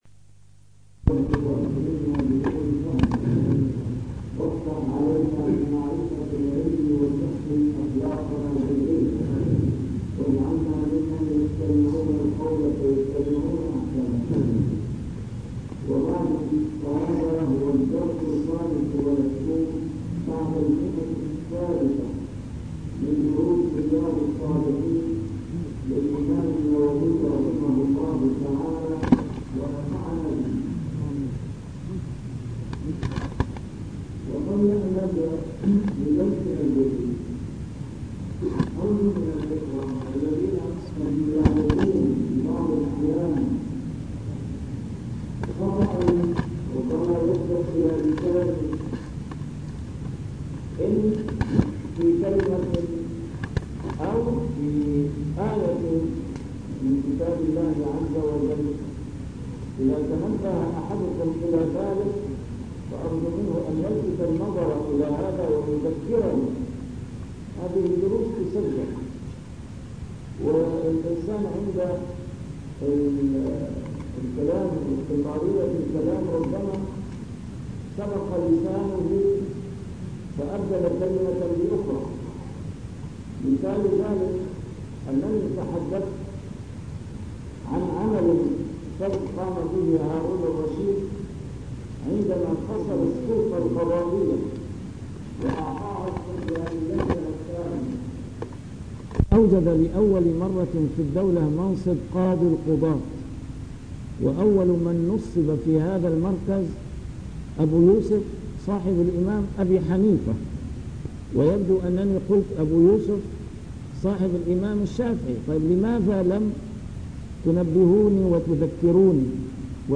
نسيم الشام › A MARTYR SCHOLAR: IMAM MUHAMMAD SAEED RAMADAN AL-BOUTI - الدروس العلمية - شرح كتاب رياض الصالحين - 325- شرح رياض الصالحين: تعظيم حرمات المسلمين